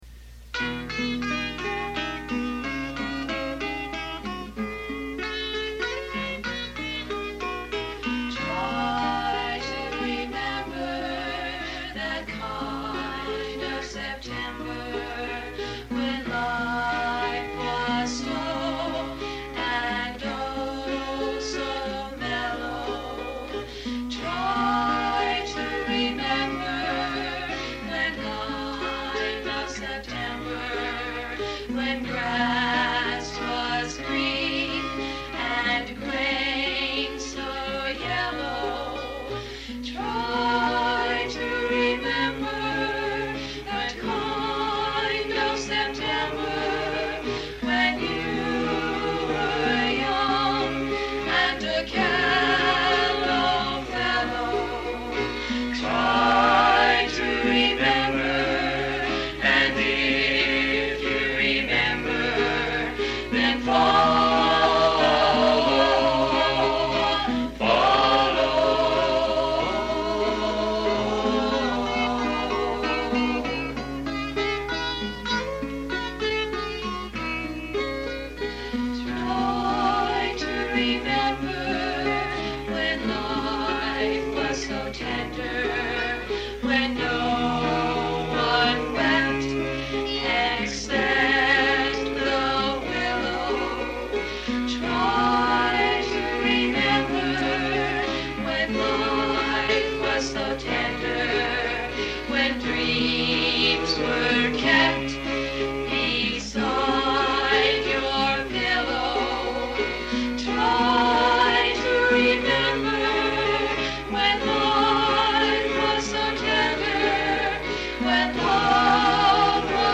Recorded live when we were 15-17 years old